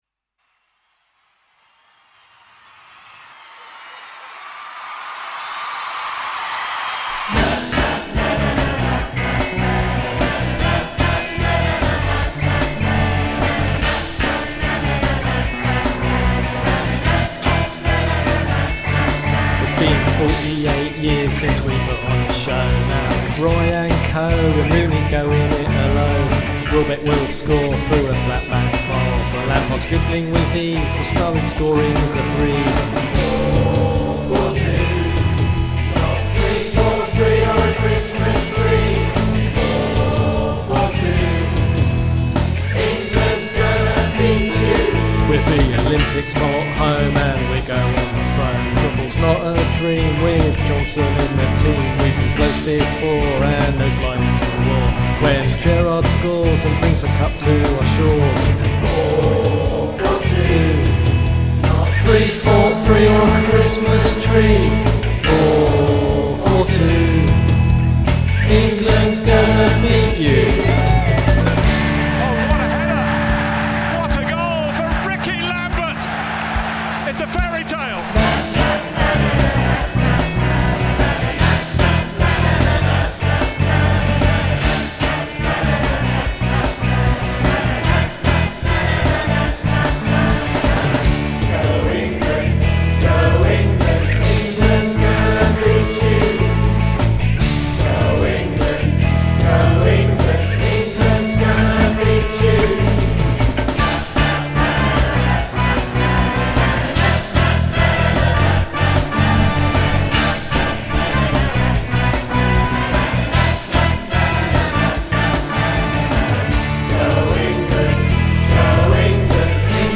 Lead vocal
The chorus